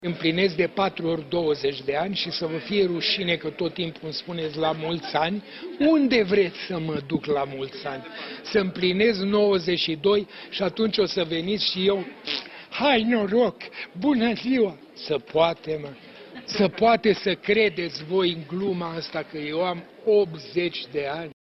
Florin Piersic a fost sărbătorit ieri-seară la Cinematograful care-i poartă numele din Cluj-Napoca, unde a fost proiectat filmul ”Parașutiștii”. Înconjurat de spectatori, Florin Piersic le-a răspuns celor care i-au adresat urări la împlinirea a 80 de ani: